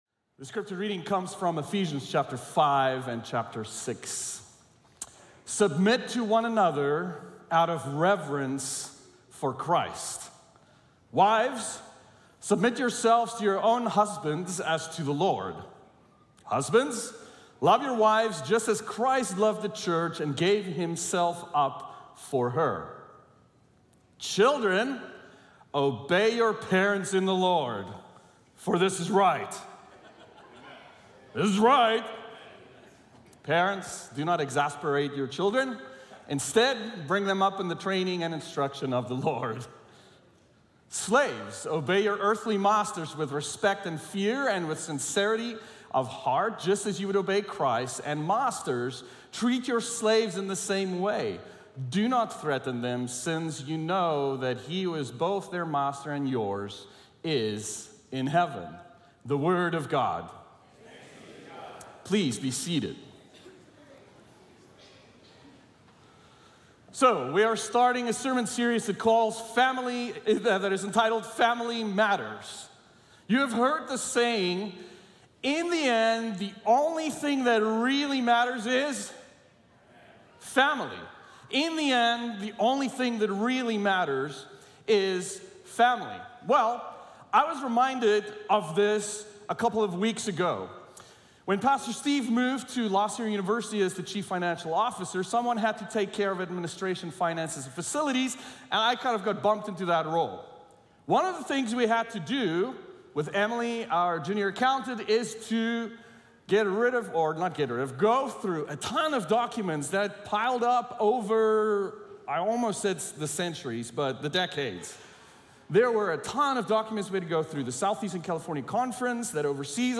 This is the sermon audio podcast for La Sierra University Church.